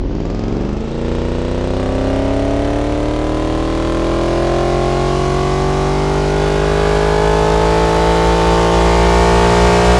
v8_09_Accel.wav